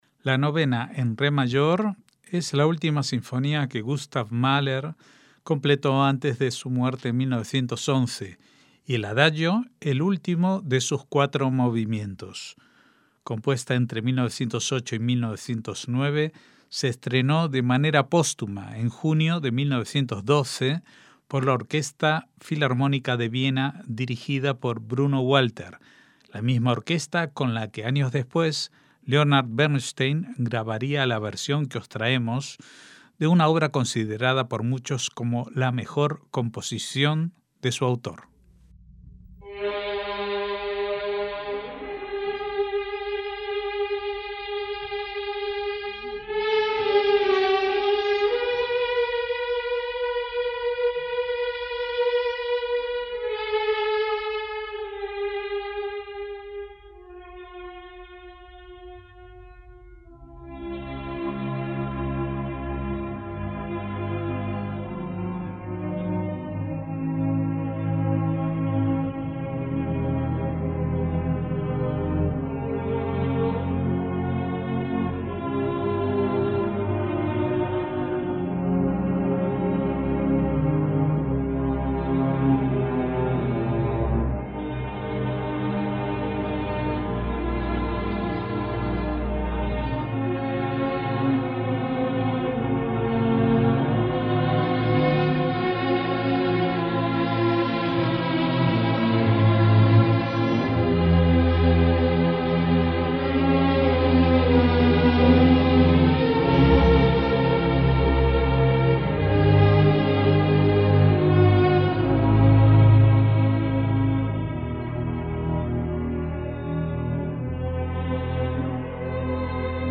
MÚSICA CLÁSICA
sinfonía